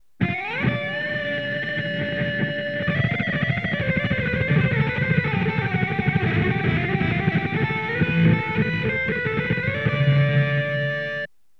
3649_Guitar_LowPass.wav